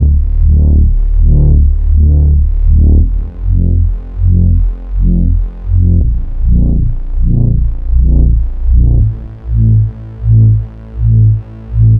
Index of /DESN275/loops/Loop Set - Aerosol - Ambient Synth Loops - F and Dm/Loops
UnderwaterChannel_80_Dm_Bass.wav